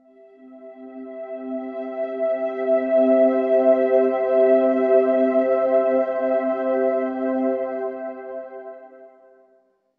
🎵 Background Music